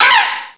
cries / rufflet.aif